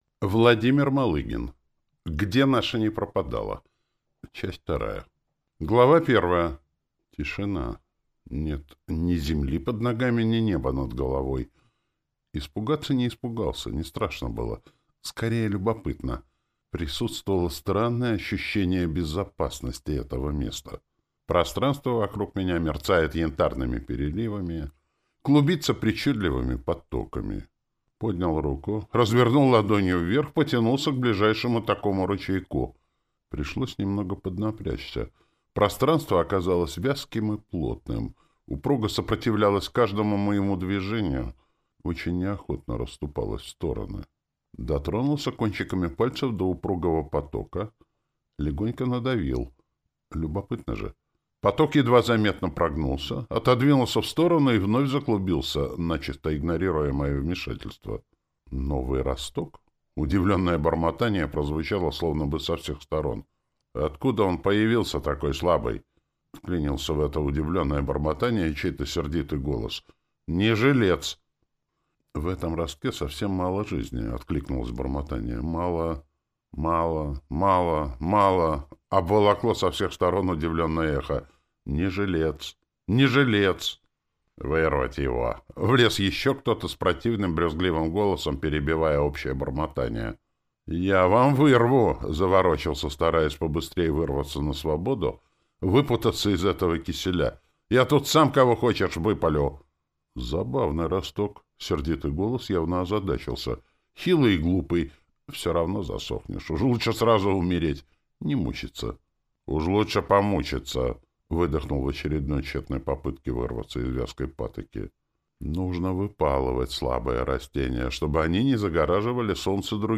Аудиокнига Где наша не пропадала. Часть вторая | Библиотека аудиокниг